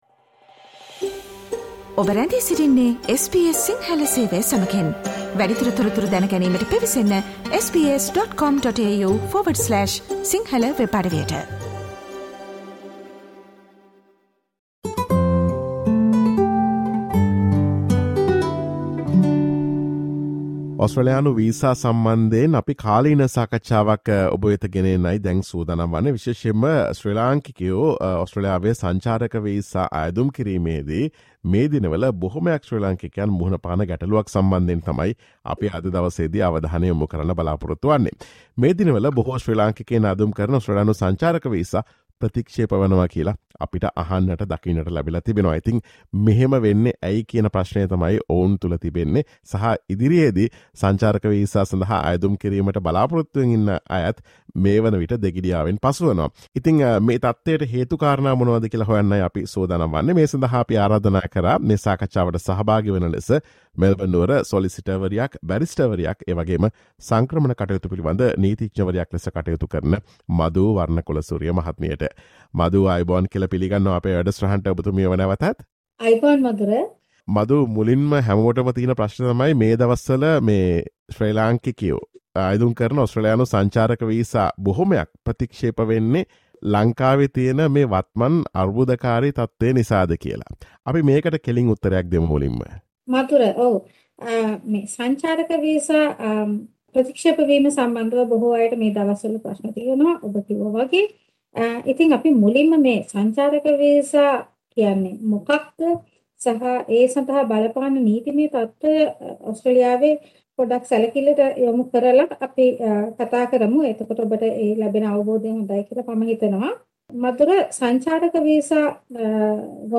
මේ දිනවල බොහෝ ශ්‍රී ලාංකිකයින් අයදුම් කරන ඔස්ට්‍රේලියානු සංචාරක වීසා ප්‍රතික්ෂේප වීම පිළිබඳ SBS සිංහල ගුවන් විදුලිය සිදුකළ සාකච්ඡාවට සවන් දෙන්න.